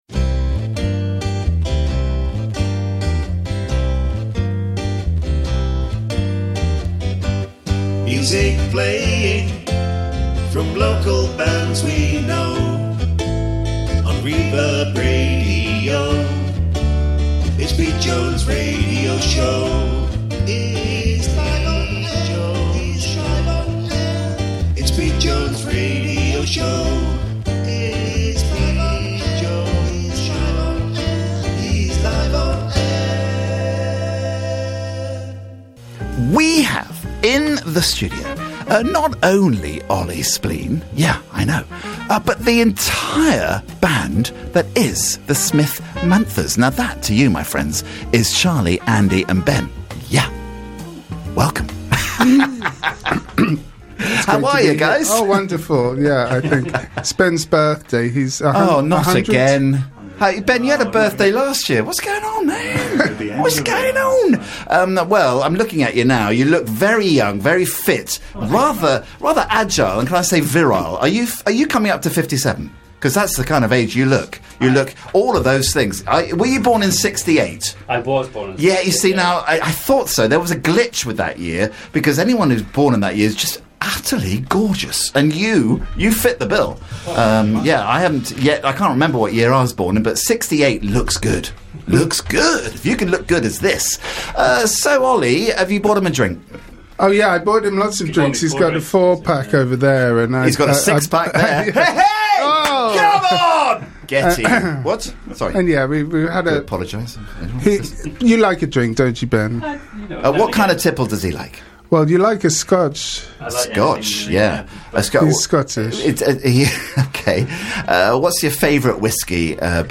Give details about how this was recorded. play out a blinding live session for us